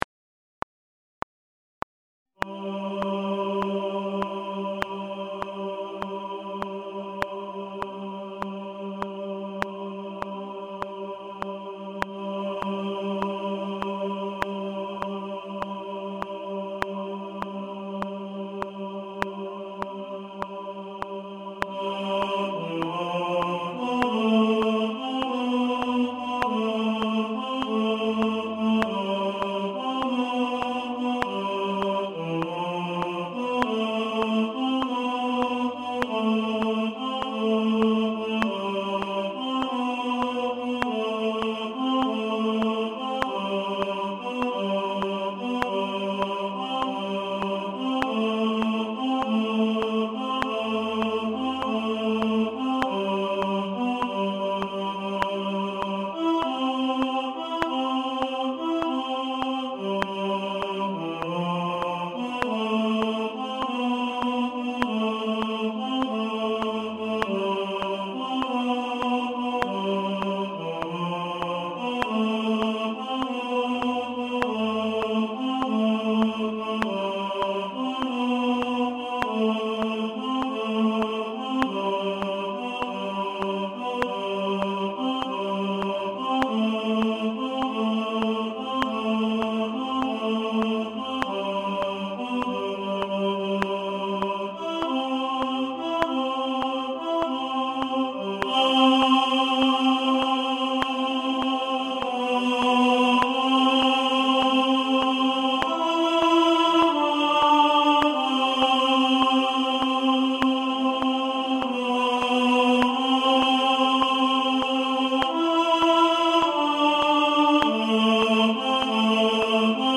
Only You – Tenor | Ipswich Hospital Community Choir